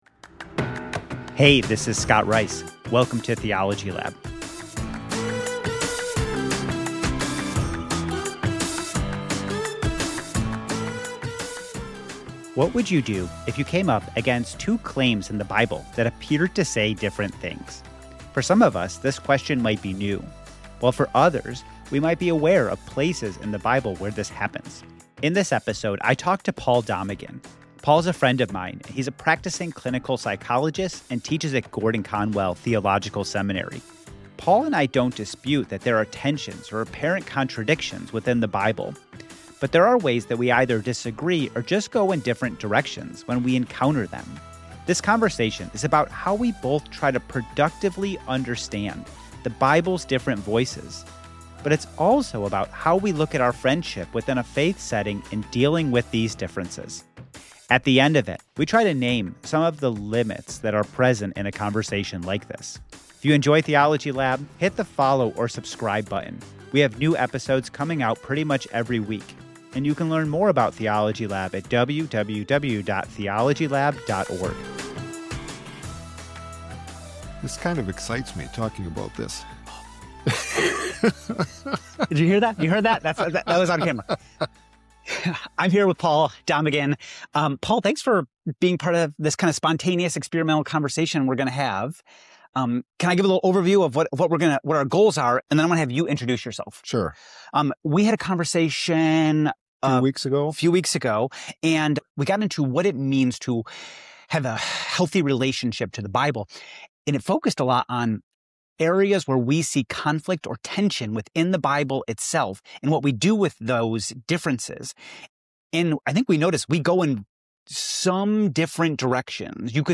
for a conversation about what to do when you encounter tensions or apparent contradictions within the Bible.